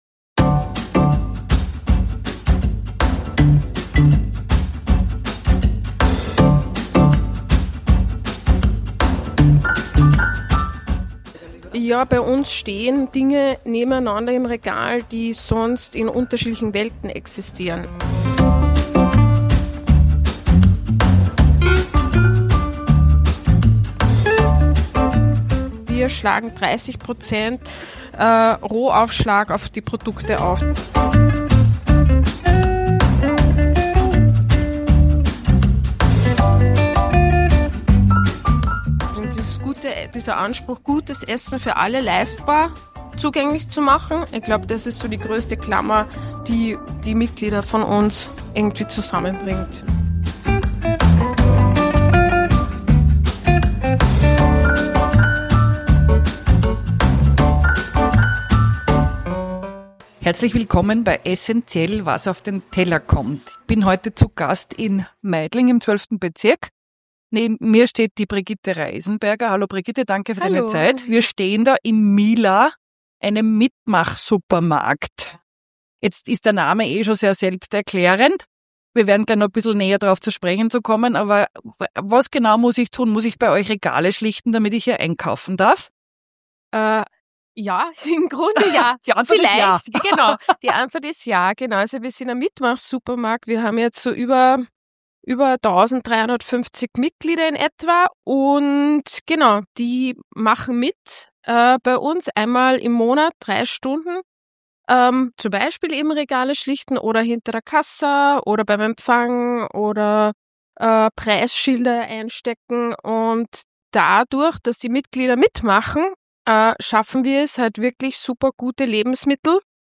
Ein Rundgang durch einen Supermarkt, der nach internationalen Modellen und Vorbildern entwickelt wurde.